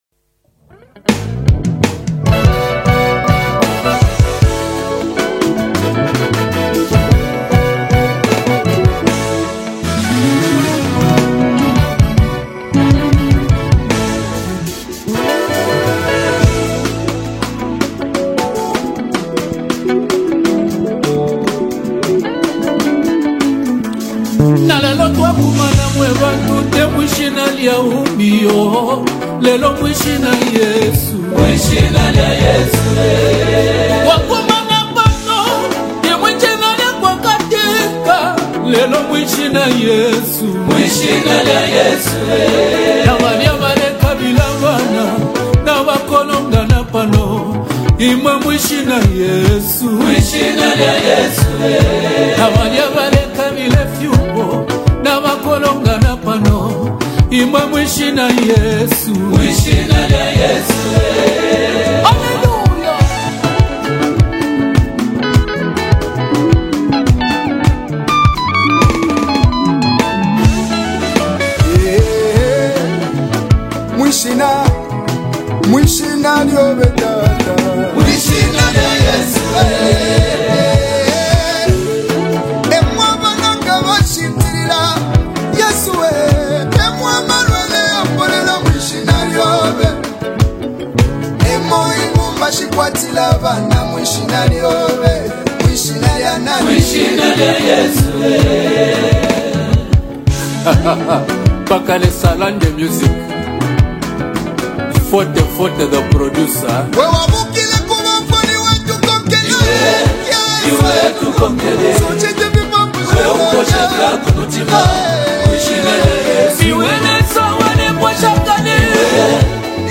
A beautiful fusion of Rumba and worship music
A collaboration of two powerful gospel voices in Zambia
📅 Category: Latest Zambian Rumba Worship Song